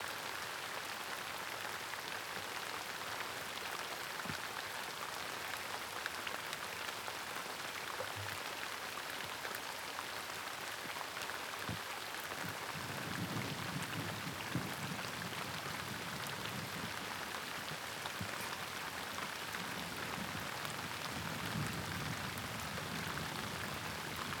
雨声.wav